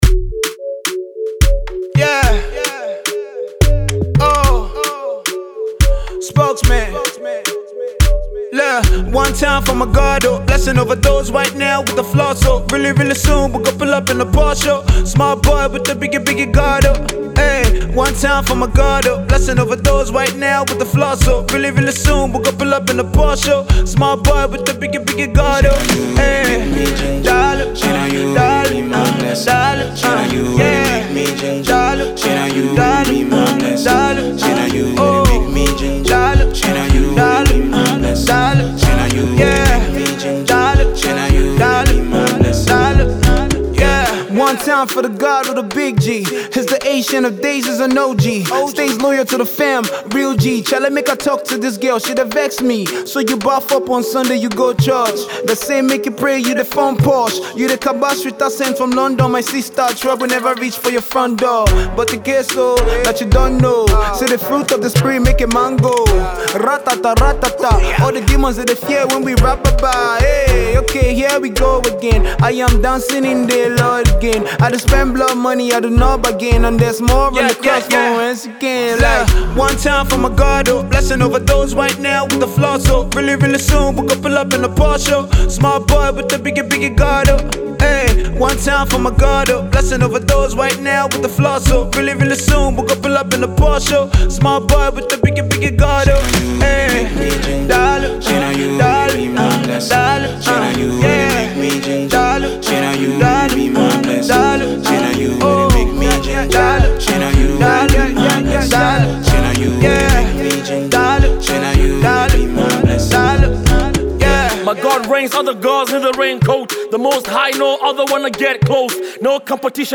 Nigerian Christian Rapper
Christian Hip Hop